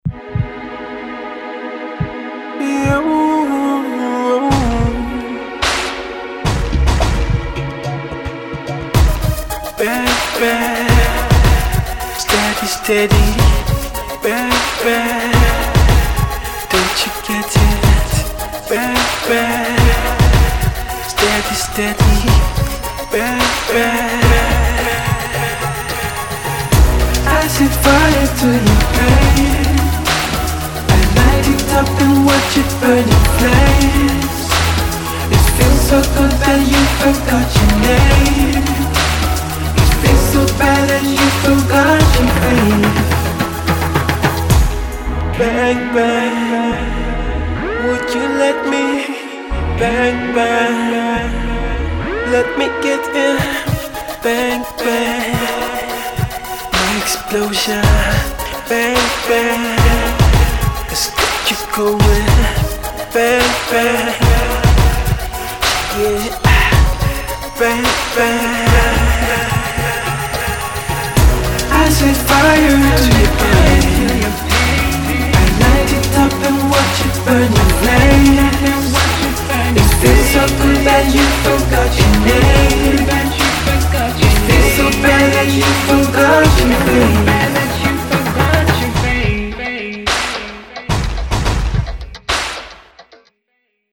the leaders of alternative R&B.